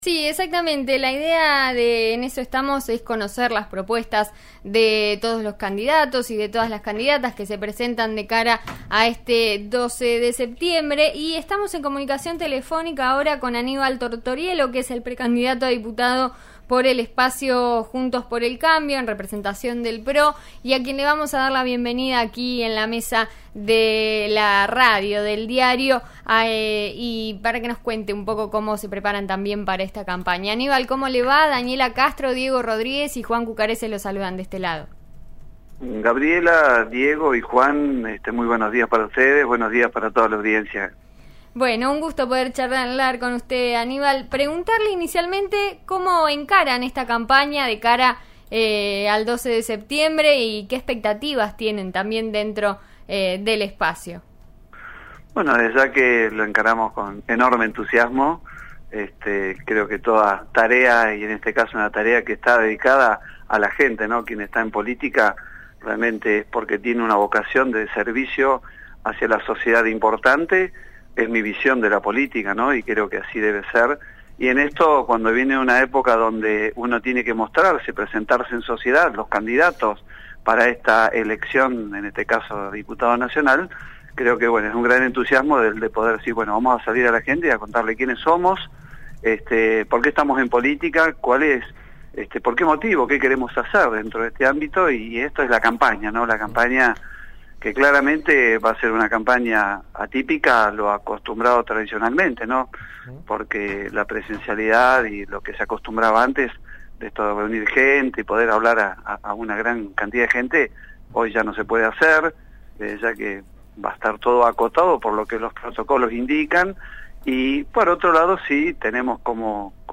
'En eso estamos' de RN Radio dialogó con Aníbal Tortoriello, del espacio Juntos por el Cambio, sobre su candidatura en las PASO.